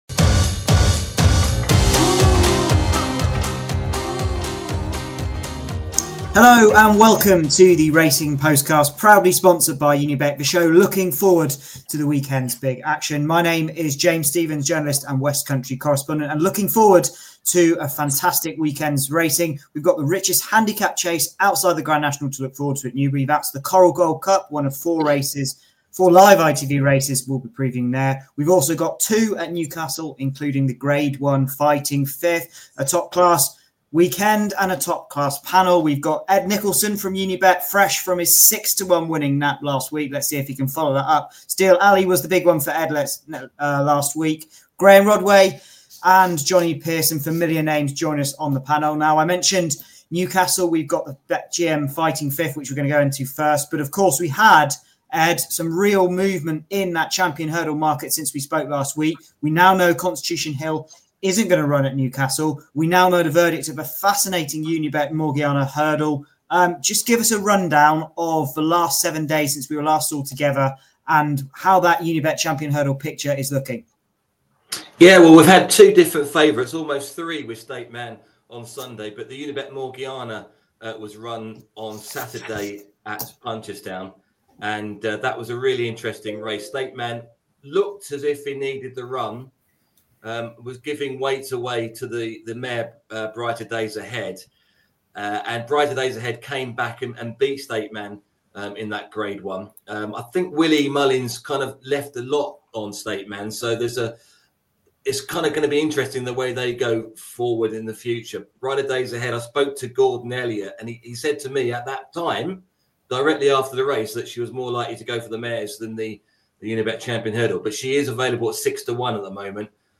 In the main part of the show, the panel preview the Coral Gold Cup, Newbury's flagship race over jumps in which a field of 14 is set to go to post.